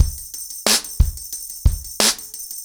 ROOTS-90BPM.3.wav